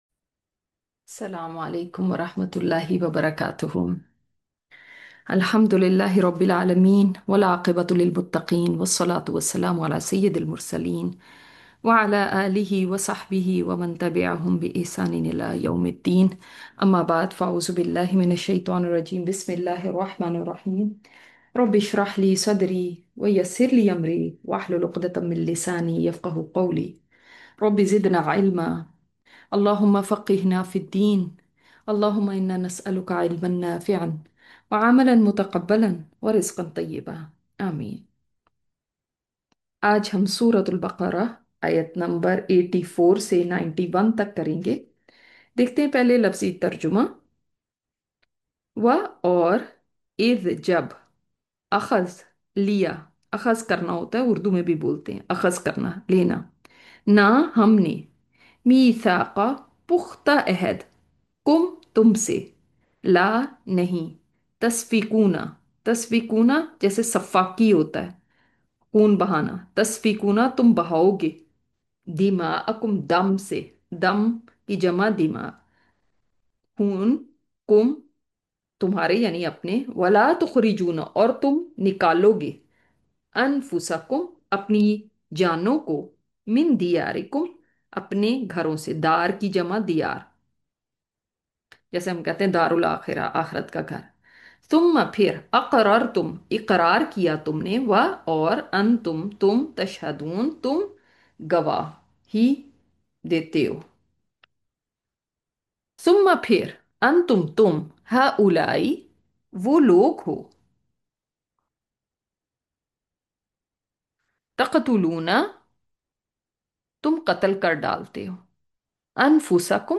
Latest Lecture